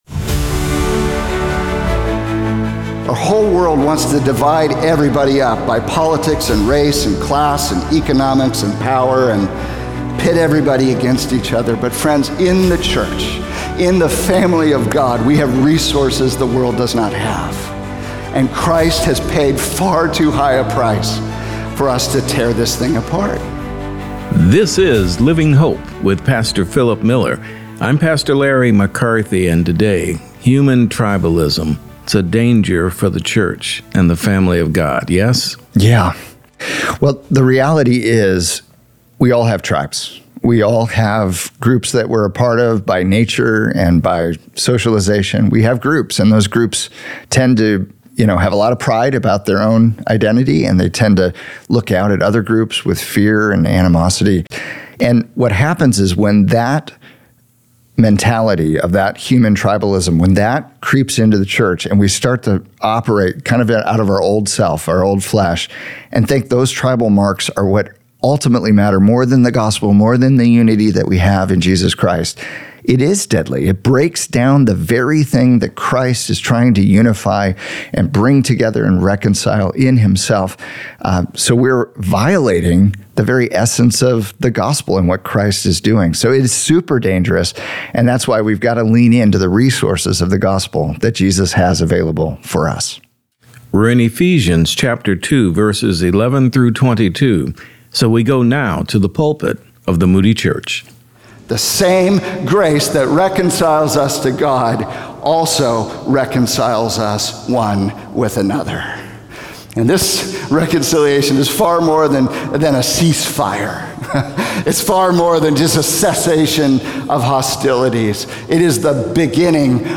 Building God’s Multiethnic Family Beyond Tribalism | Radio Programs | Living Hope | Moody Church Media